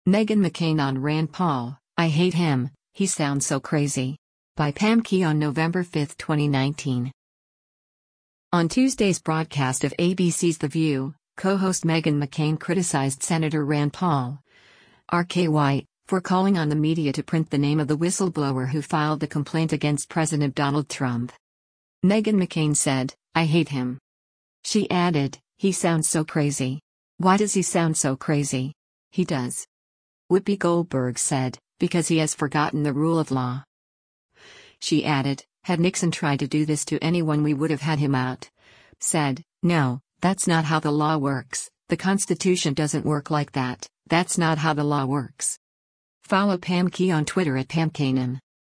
On Tuesday’s broadcast of ABC’s “The View,” co-host Meghan McCain criticized Sen. Rand Paul (R-KY) for calling on the media to print the name of the whistleblower who filed a complaint against President Donald Trump.